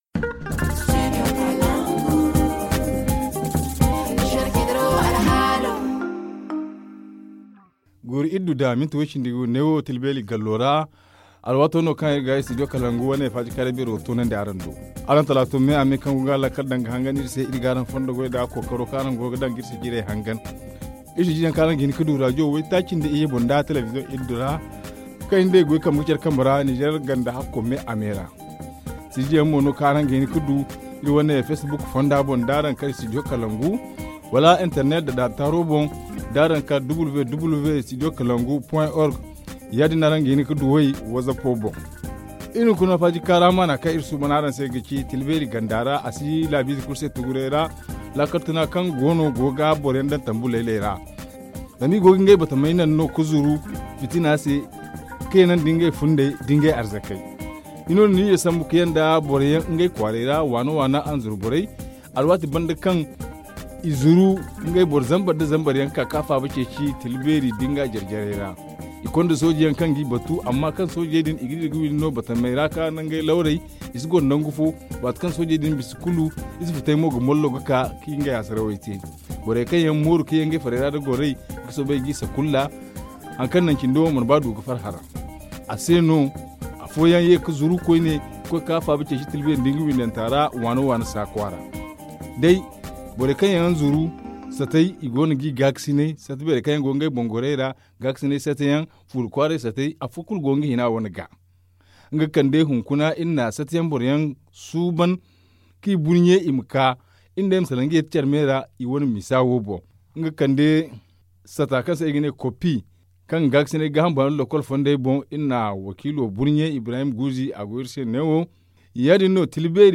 Le forum en zarma